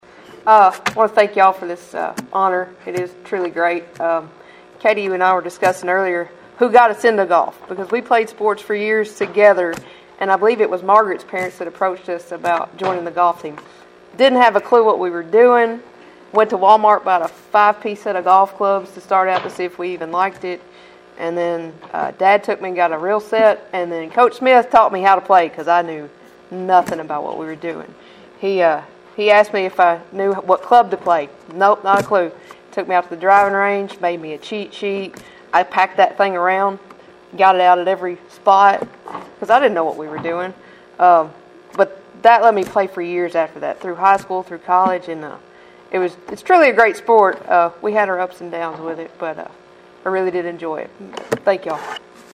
acceptance speech